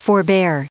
added pronounciation and merriam webster audio
1766_forbear.ogg